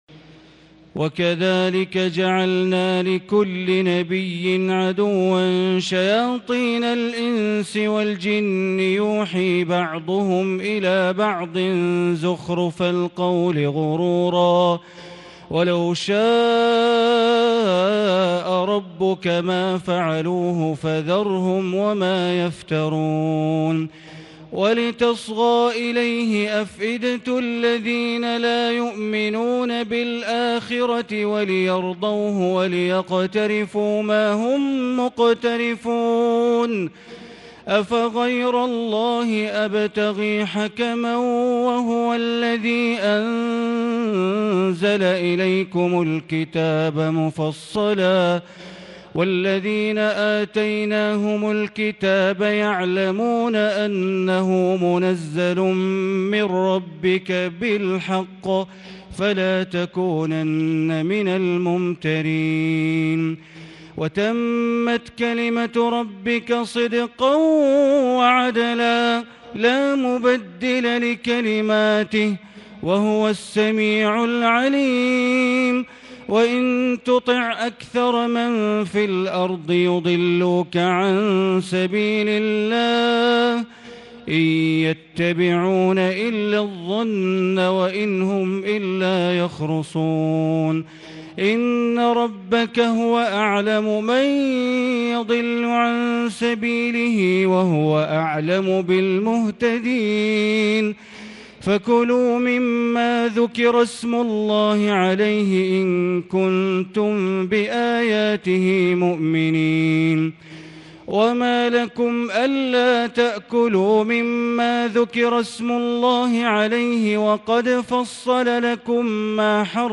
تهجد ليلة 28 رمضان 1440هـ من سورتي الأنعام (112-165) و الأعراف(1-30) Tahajjud 28 st night Ramadan 1440H from Surah Al-An’aam and Al-A’raf > تراويح الحرم المكي عام 1440 🕋 > التراويح - تلاوات الحرمين